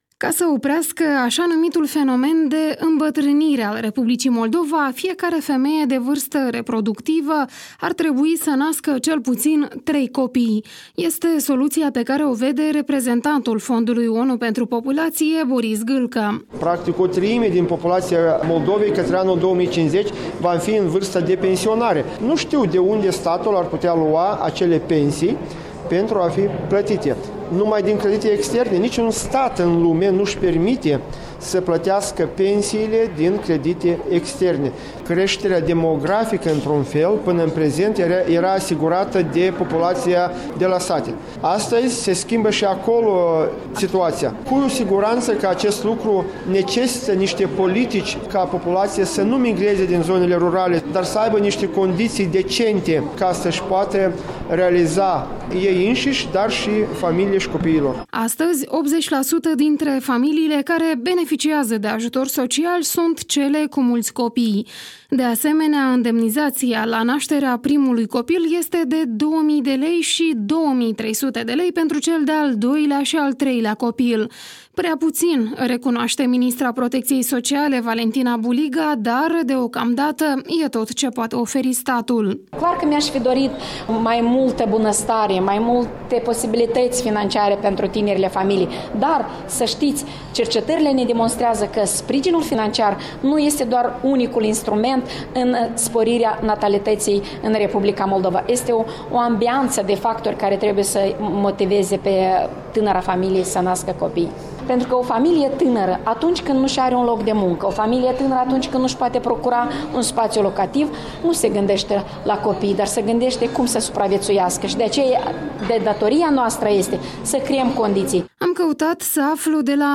Am căutat să aflu de la mai mulţi părinţi pe care i-am întâlnit în stradă ce i-ar motiva să crească mai mulţi copiii?